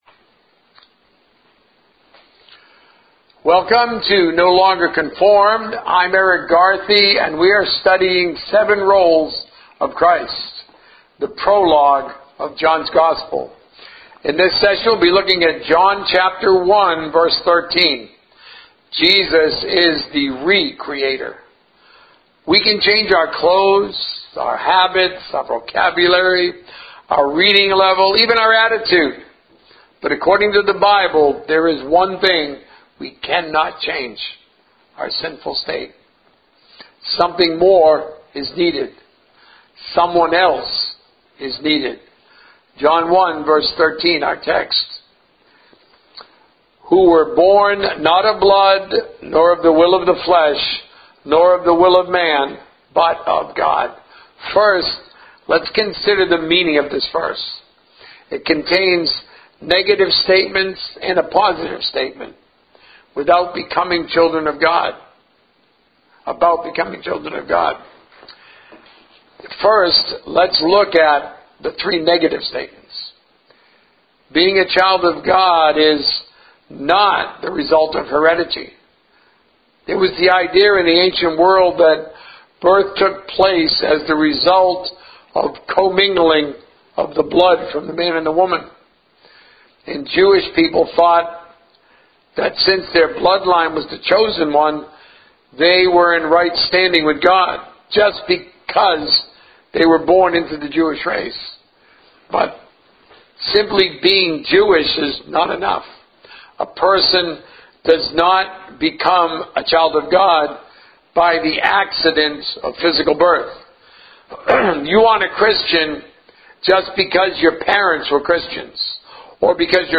Sermons - No Longer Conformed